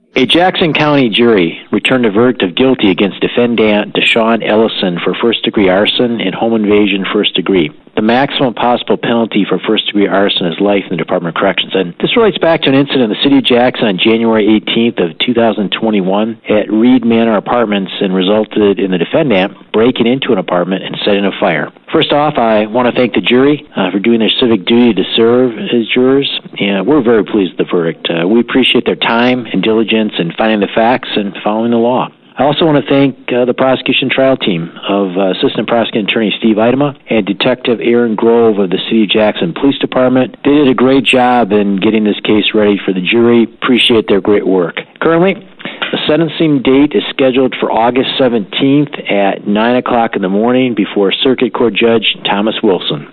Jackson County Prosecutor, Jerry Jarzynka on WKHM